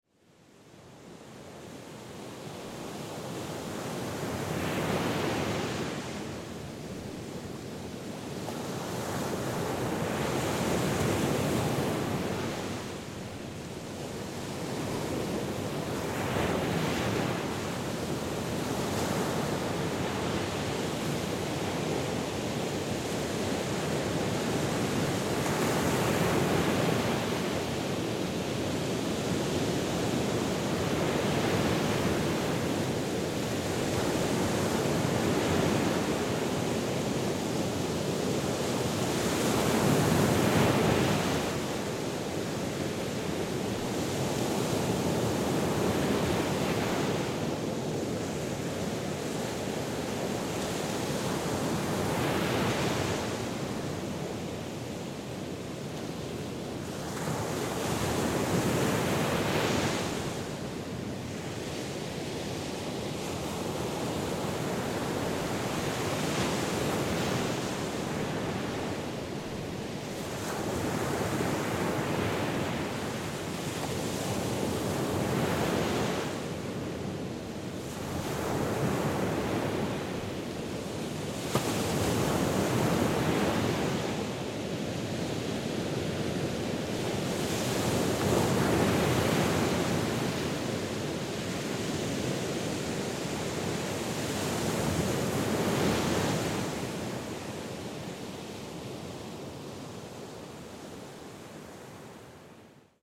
Звуки океана
Поздний вечер у океана под звездным небом